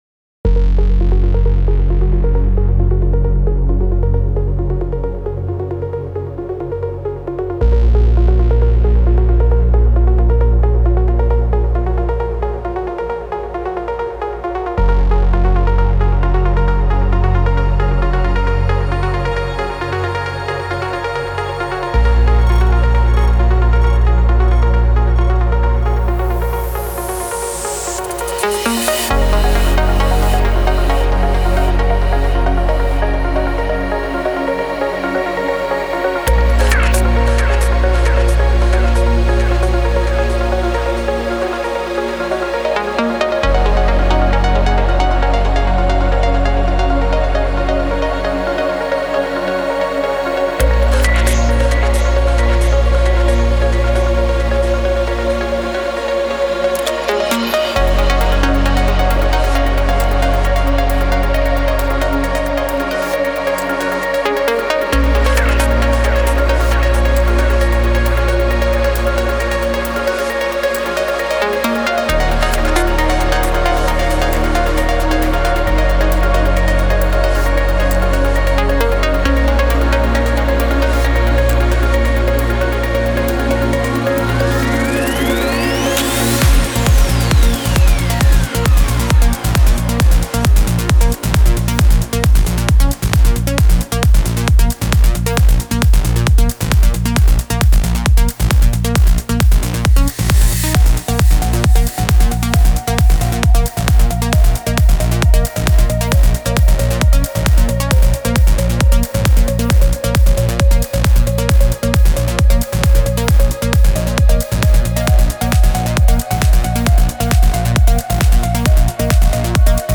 Progressive_Trance___Melodic_Progressive